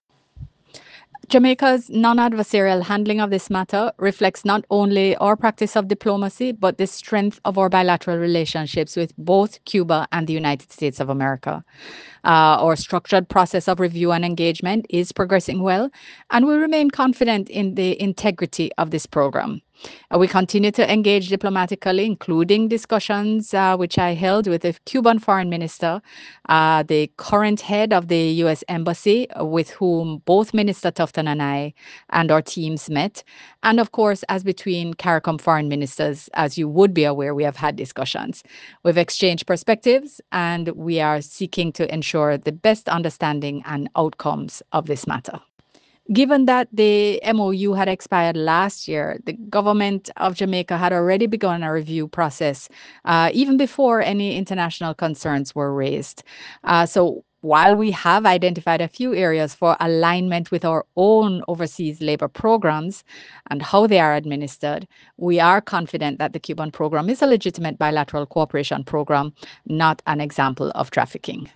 In an early morning statement updating the nation on the Government’s efforts, Minister Johnson Smith emphasised that a review of the programme had been initiated well before international scrutiny had commenced.
Minister-Johnson-Smith-speaks-on-Medical-Cooperation-Programme.mp3